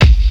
Kick_20.wav